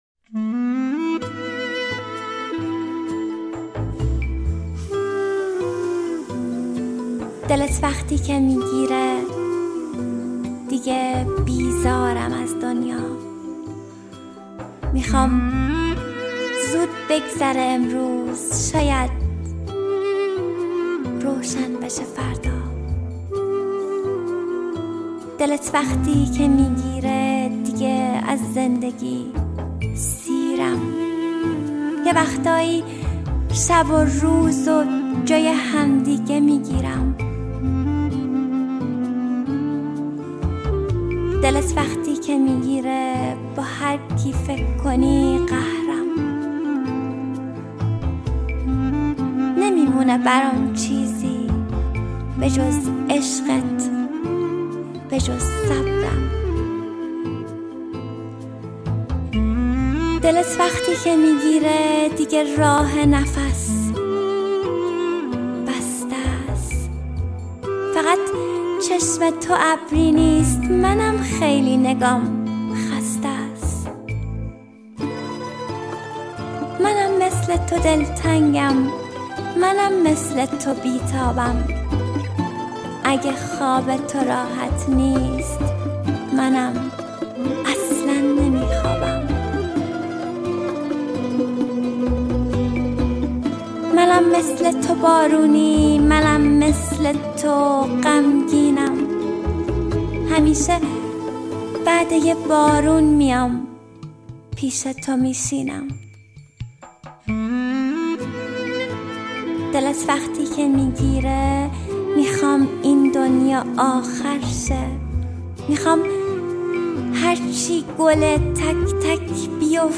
دانلود دکلمه دلت وقتی که میگیره از مریم حیدرزاده
گوینده :   [مریم حیدرزاده]